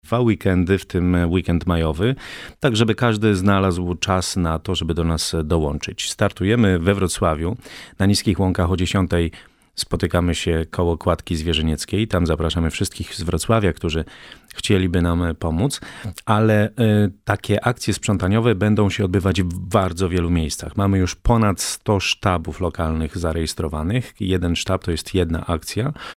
W studiu Radia Rodzina